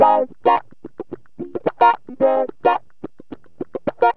GTR 14A#M110.wav